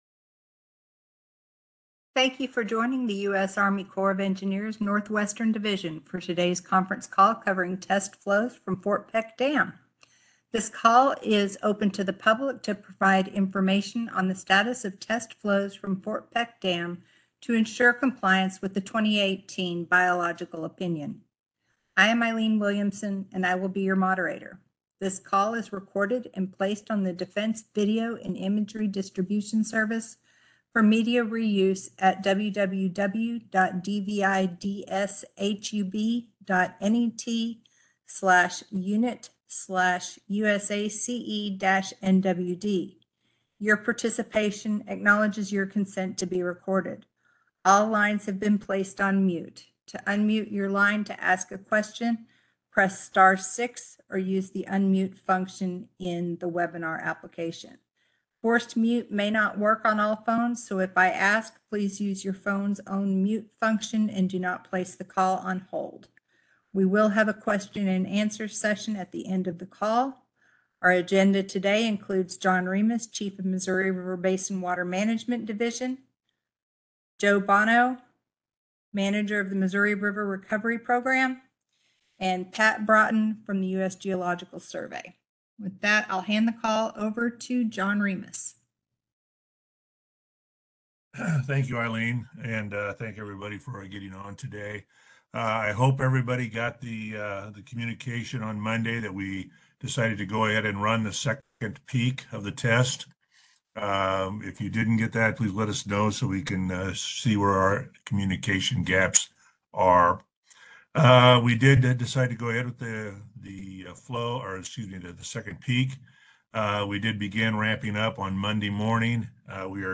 Missouri River Basin Water Management - Fort Peck Test Flows - Weekly Call